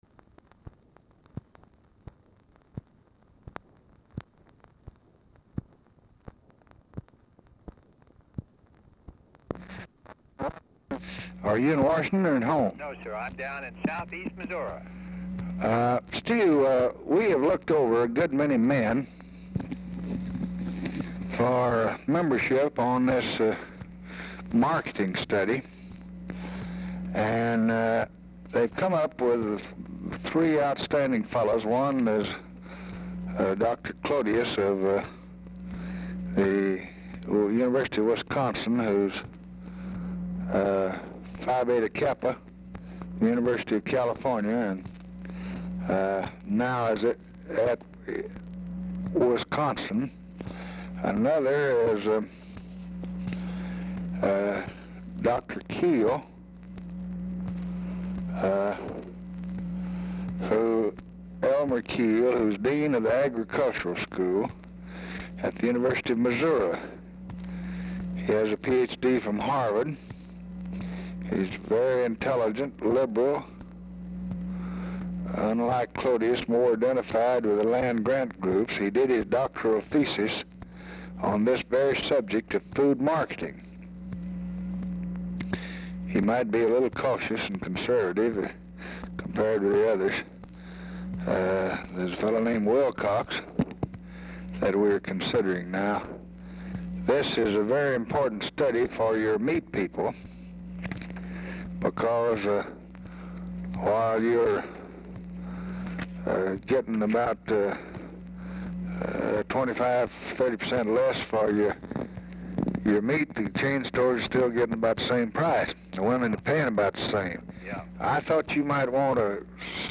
Telephone conversation # 4211, sound recording, LBJ and STUART SYMINGTON, 7/10/1964, 7:48PM
LBJ IS MEETING WITH RALPH DUNGAN AT TIME OF CALL, TALKS BRIEFLY WITH DUNGAN DURING CALL
Format Dictation belt
Oval Office or unknown location